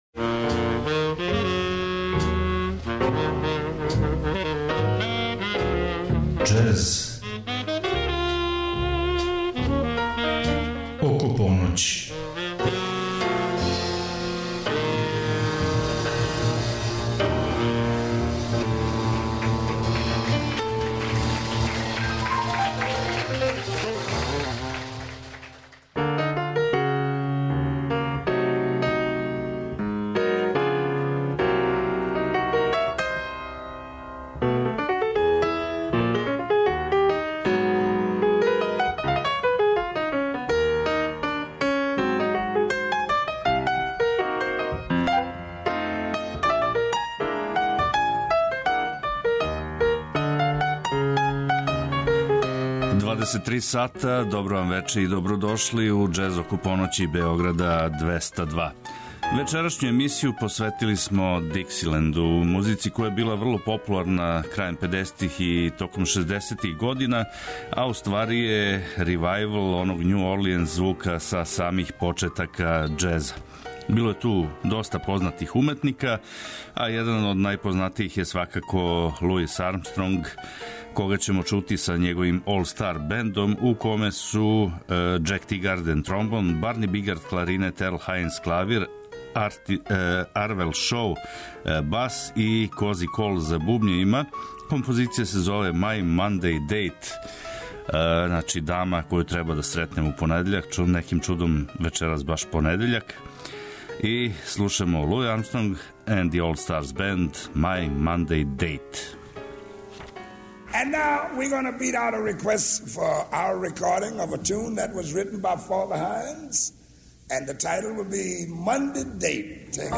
Емисија је магазинског типа и покрива све правце џез музике, од Њу Орлиенса, преко мејнстрима, до авангардних истраживања. Теме су разноврсне - нова издања, легендарни извођачи, снимци са концерата и џез клубова, архивски снимци...